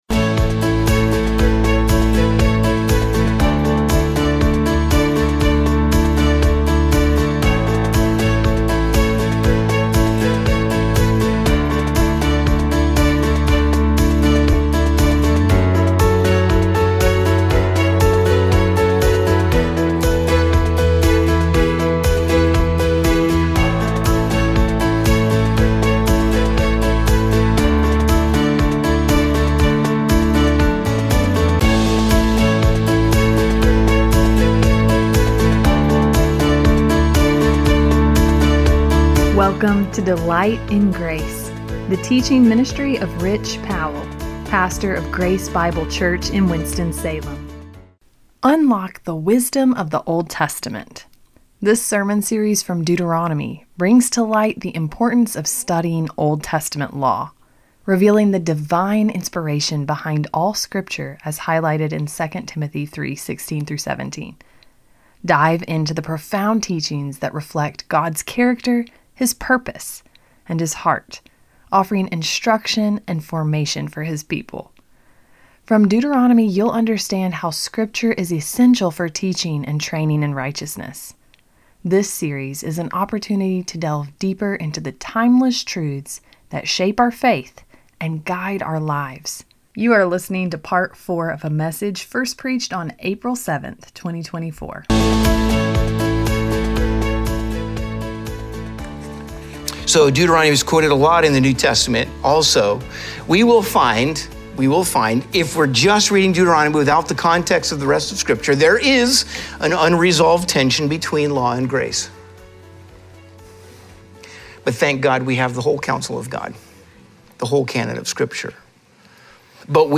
This sermon series from Deuteronomy brings to light the importance of studying Old Testament law, revealing the divine inspiration behind all Scripture as highlighted in 2 Timothy 3:16-17.